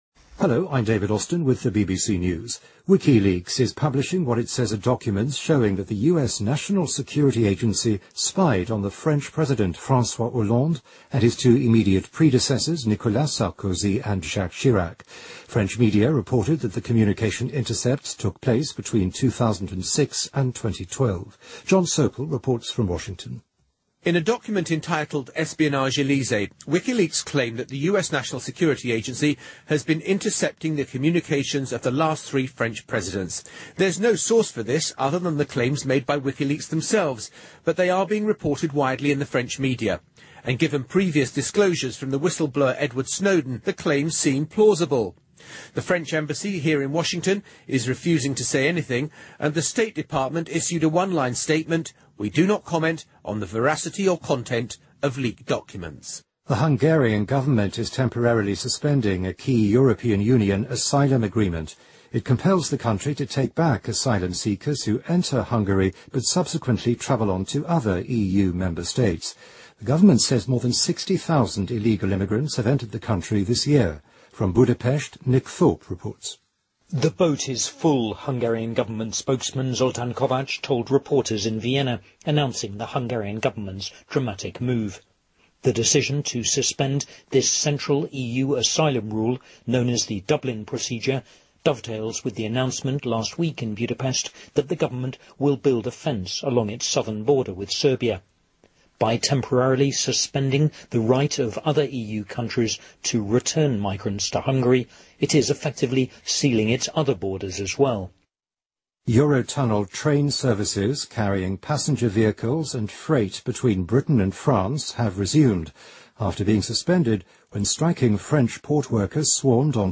BBC news,维基解密称美国监听法国三任总统